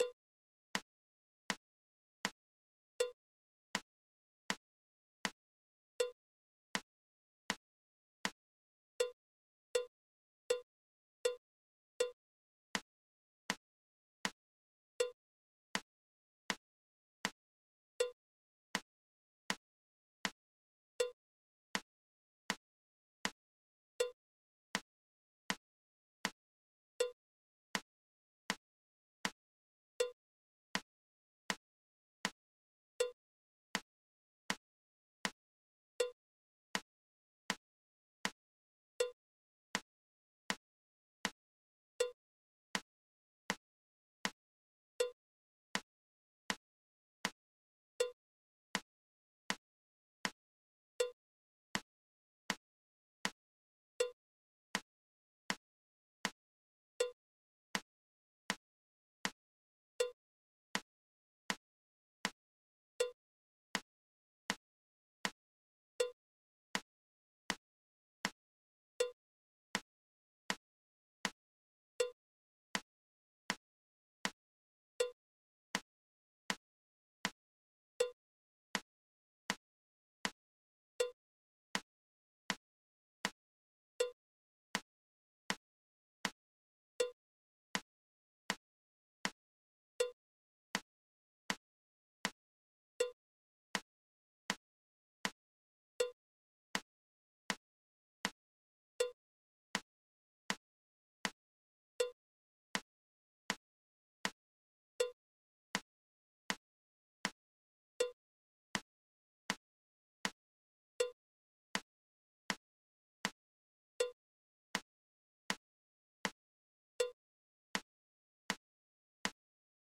Come citato nel libro è disponibile la traccia di metronomo scaricabile gratuitamente al link: Click Track Workout .
Clicktrack-Workout-A-Week-1.mp3